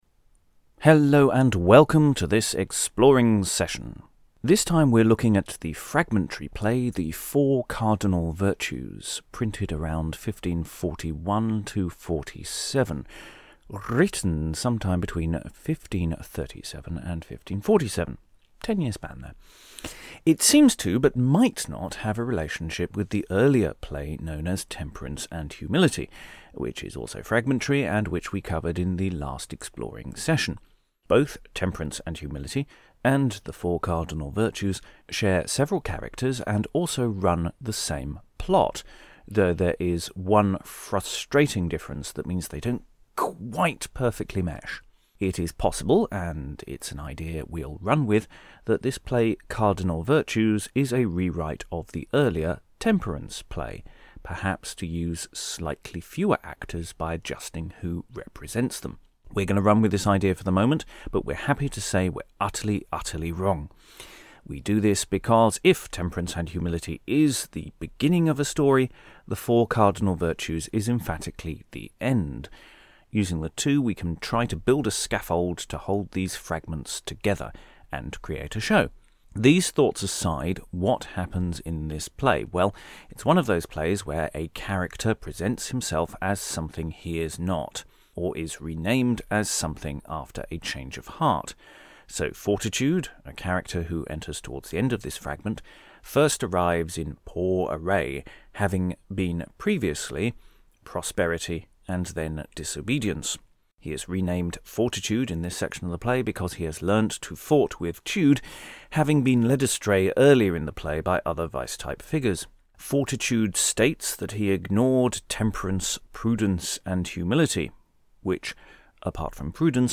From our Exploring workshop, where we read through the text of this fragment of a lost play for future use towards an audio/stage show.